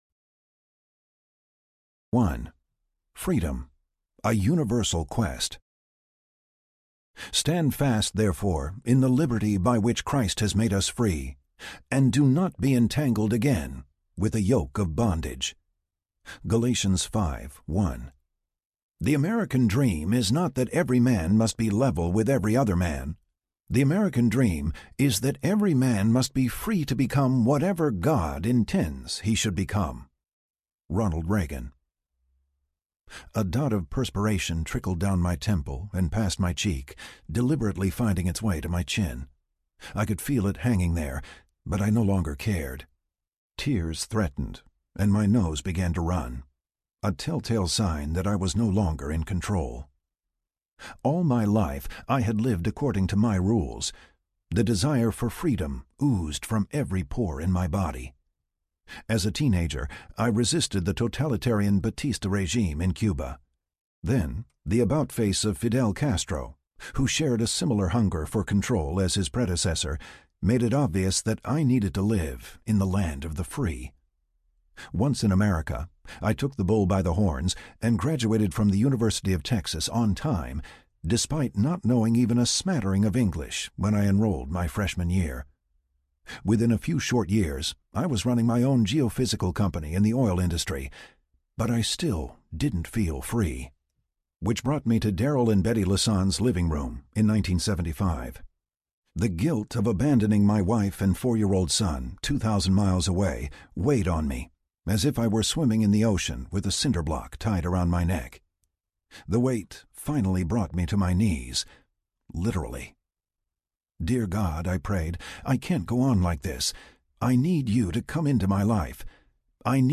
A Time for Action Audiobook
Narrator
7.2 Hrs. – Unabridged